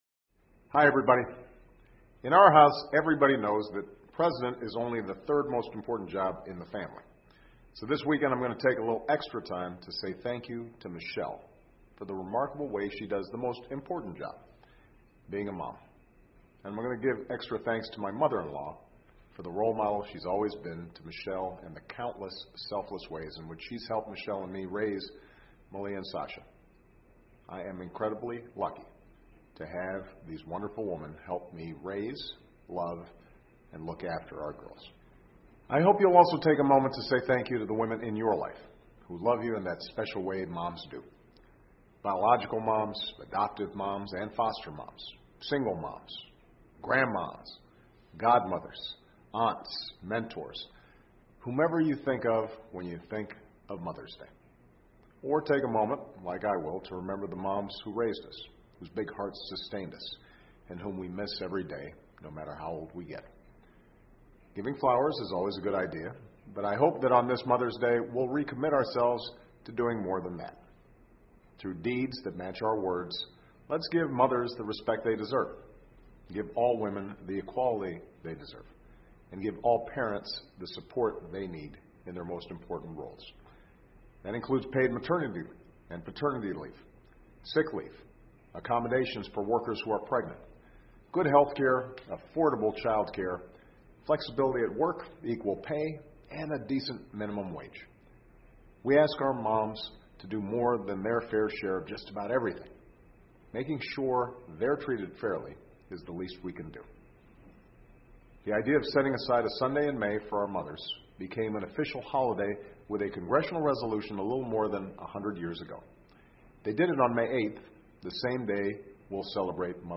奥巴马每周电视讲话：总统母亲节祝福全天下母亲节日快乐 听力文件下载—在线英语听力室